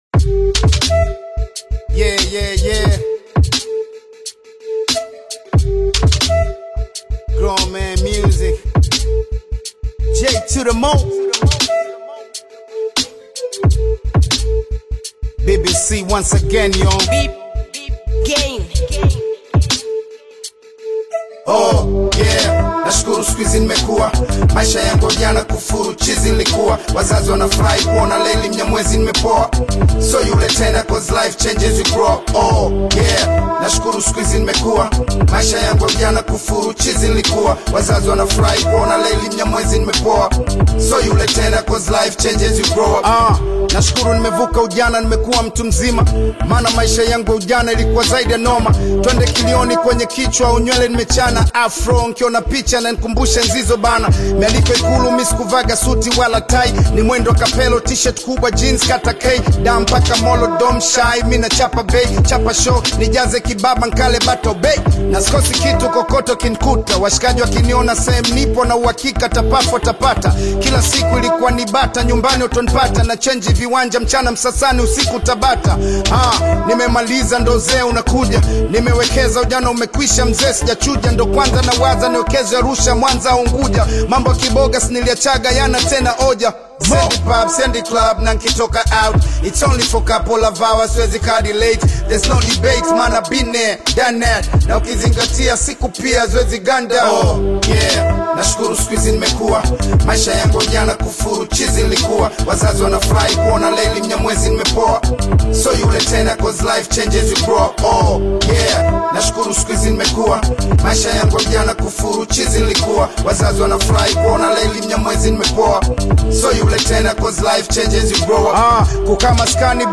The legendary Tanzanian Hip Hop heavyweight